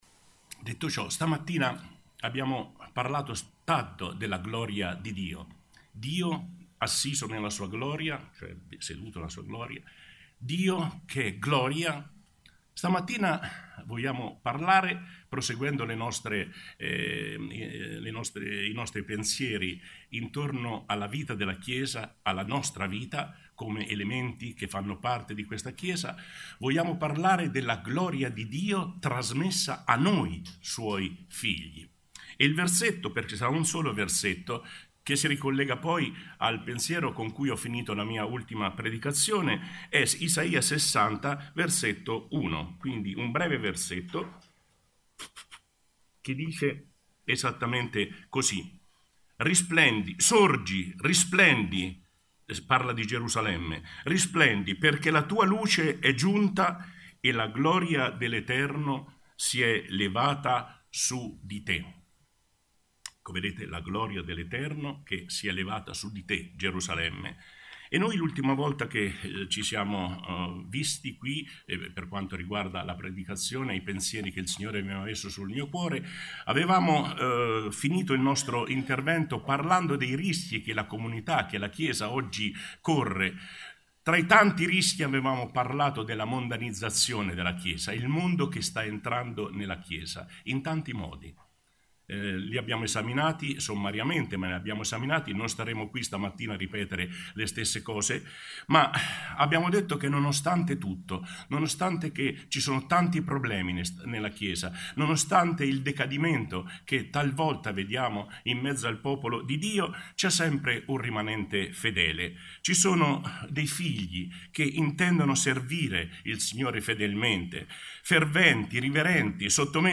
Bible Text: Isaia 60:1 | Preacher